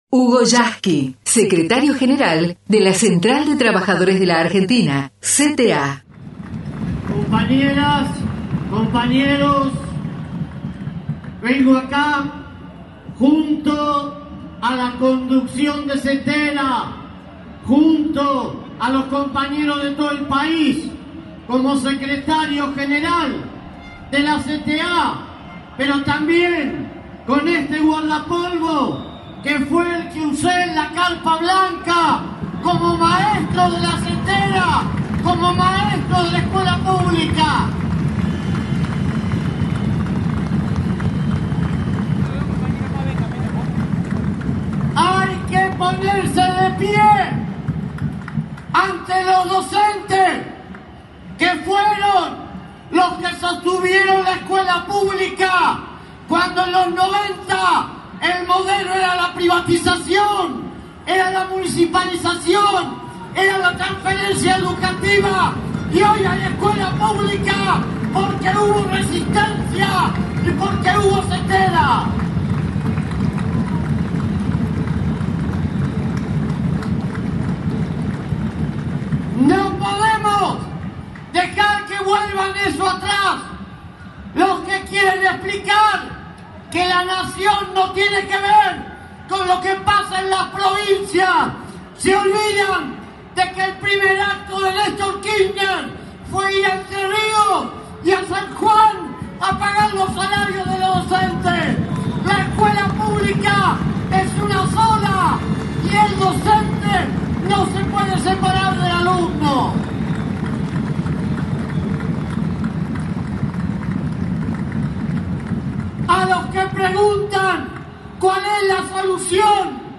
Discurso del compañero secretario General de la CTA en la jornada nacional de lucha y movilización de los docentes